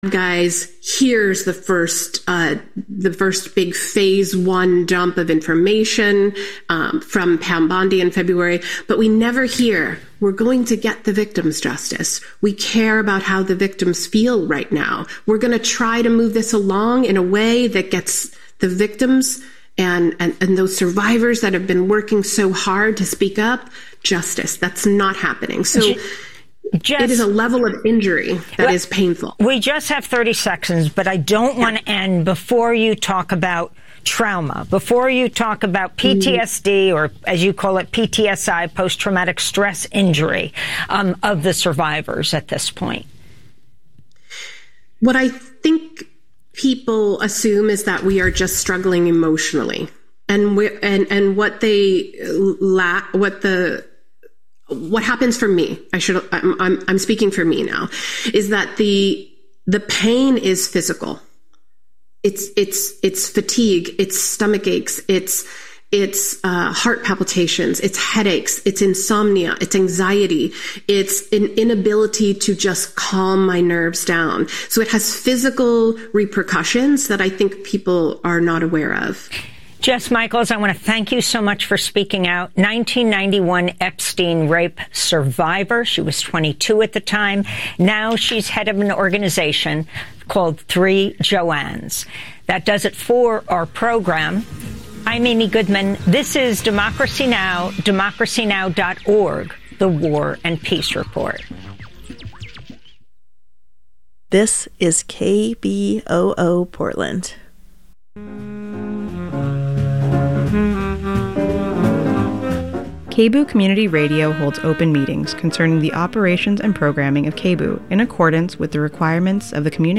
Evening News on 08/08/25